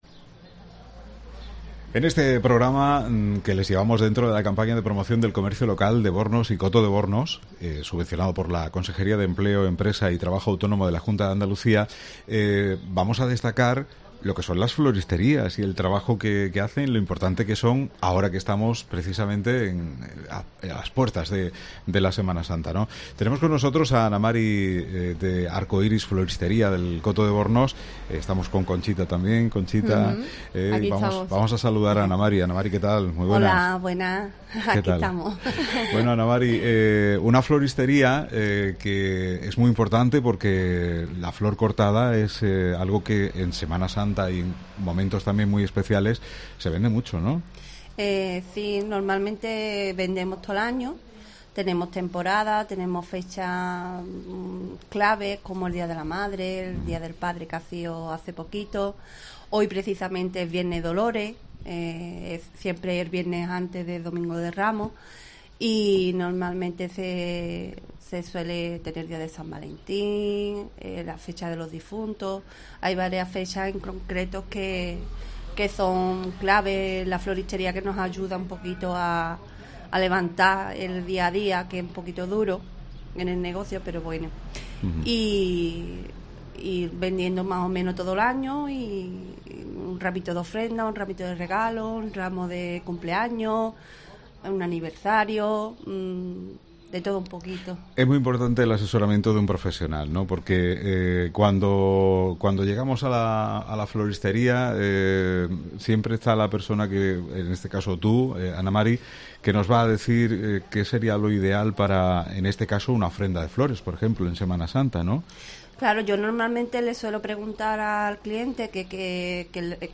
COPE Provincia de Cádiz desde la Plaza Artesanía del Coto de Bornos en la campaña de promoción del Comercio Local de Bornos y Coto de Bornos subvencionada por la Consejería de Empleo, Empresa y Trabajo Autónomo de la Junta de Andalucía dirigidas a Ayuntamientos y destinadas a impulsar la mejora, modernización y promoción del Comercio y la artesanía en Andalucía.